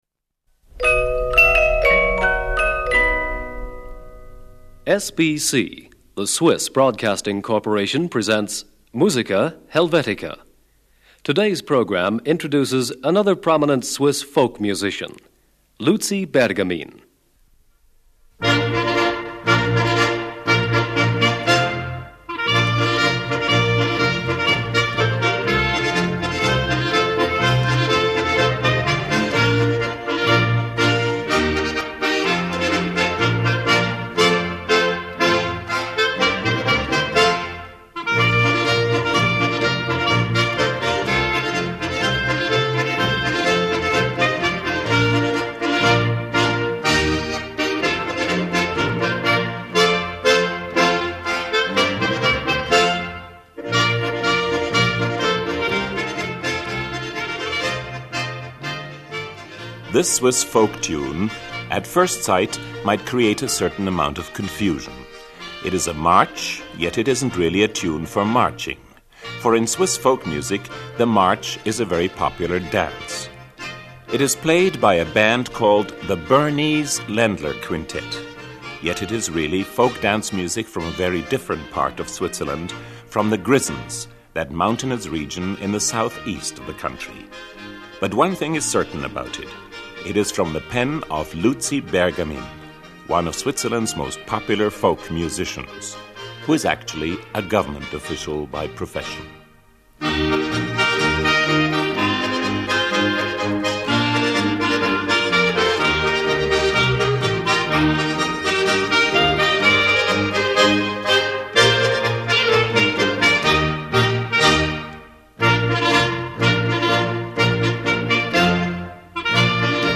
Trad.
Old-time Schottisch.